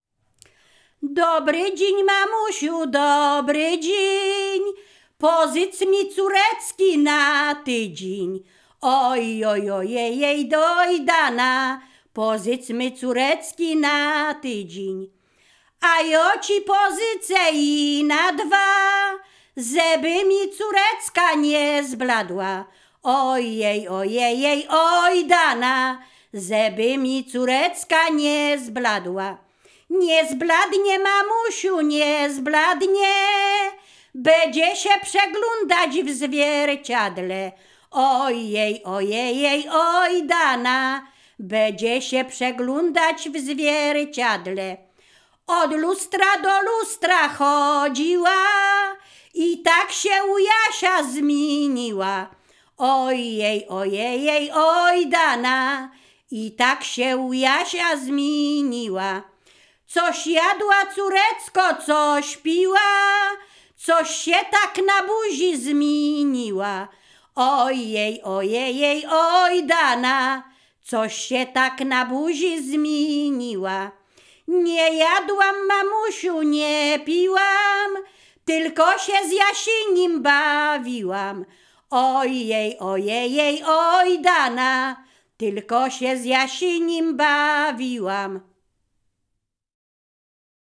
Ziemia Radomska
liryczne miłosne żartobliwe